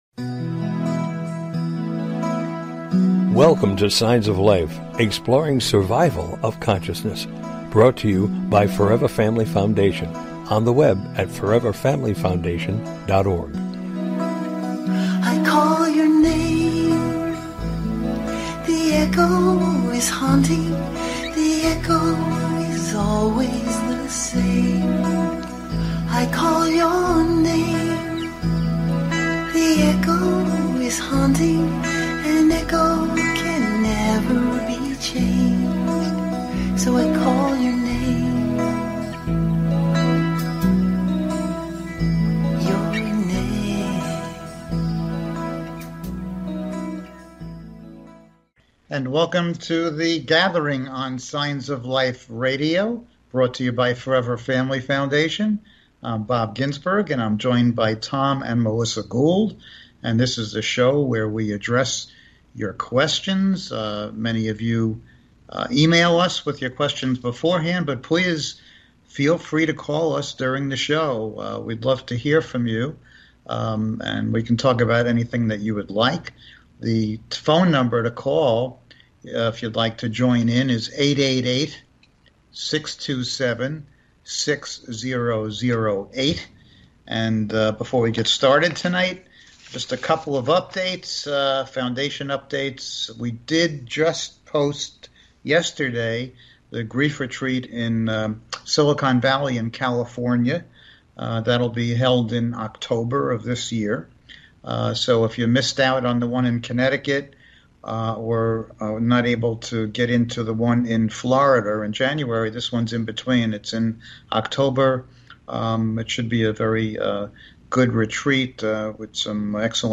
The Gathering: A Discussion about After Life Communication